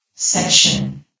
S.P.L.U.R.T-Station-13/sound/vox_fem/section.ogg
* New & Fixed AI VOX Sound Files